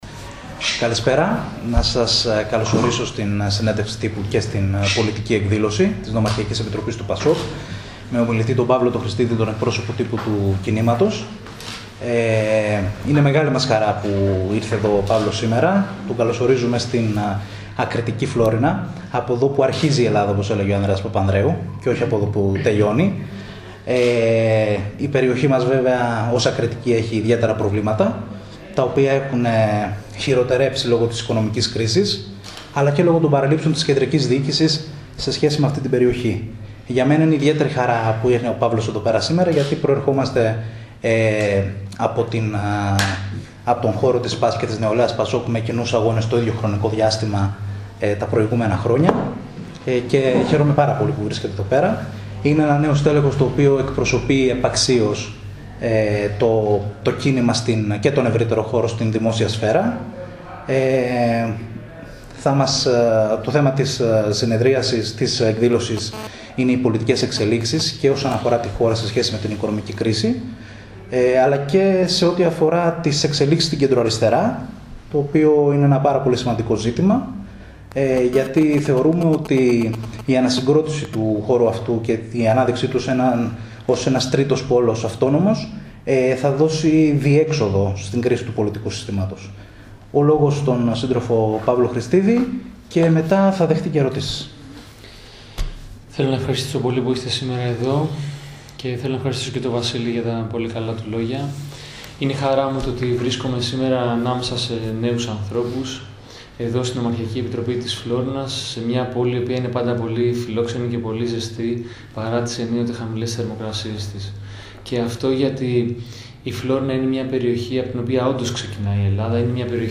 Στις τρέχουσες πολιτικές εξελίξεις στην Ελλάδα σε σχέση με την πορεία της Εθνικής Οικονομίας αλλά και στις εξελίξεις στην Κεντροαριστερά αναφέρθηκε στην ομιλία του ο εκπρόσωπος τύπου του ΠΑΣΟΚ Παύλος Χρηστιδης από την Φλώρινα στο πλαίσιο πολιτικής εκδήλωσης που διοργάνωσε η Ν.Ε ΠΑΣΟΚ Φλώρινας.